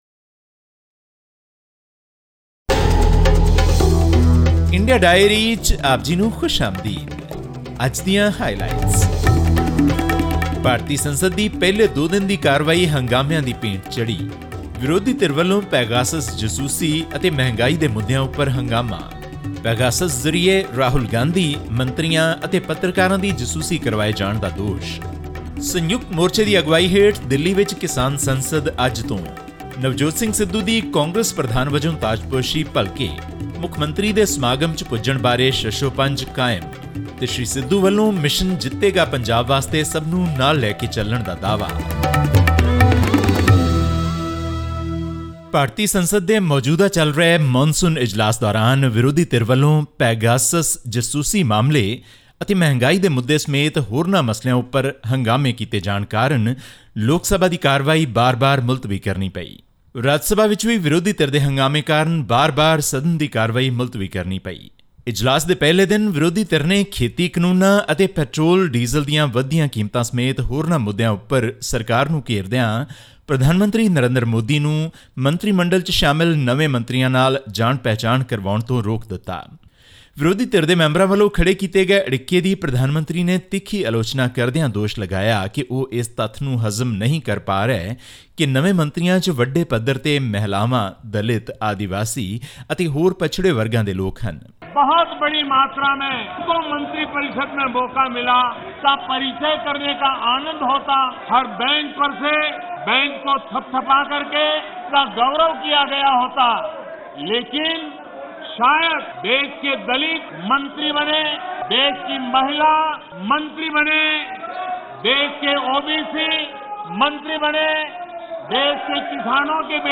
The proceedings of both the Lok Sabha and Rajya Sabha continued to stay disrupted on Thursday as opposition MPs protested over a number of subjects, including the Pegasus snooping controversy and also raised the voices to repeal the three controversial farm laws. All this and more in our weekly news segment from India.
Click on the player at the top of the page to listen to the news bulletin in Punjabi.